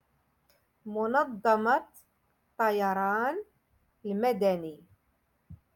Moroccan Dialect-Rotation Six- Lesson Nineteen